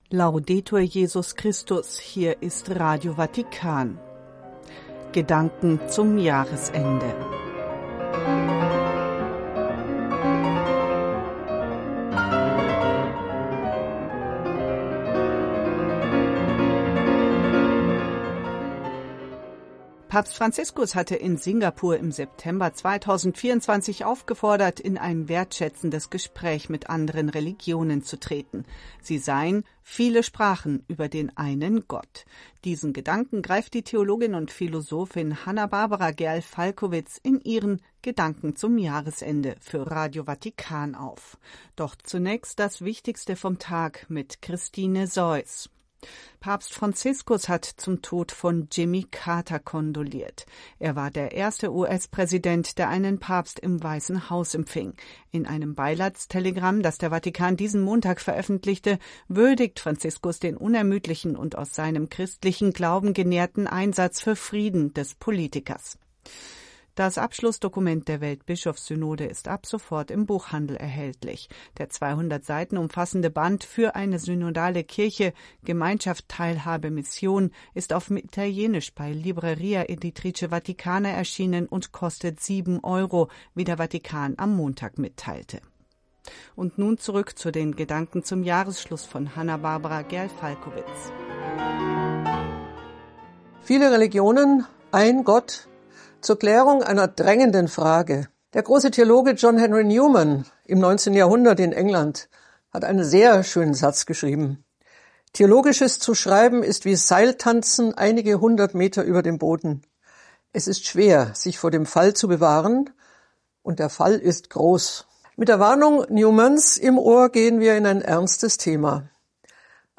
Treffpunkt Weltkirche - Nachrichtenmagazin (18 Uhr).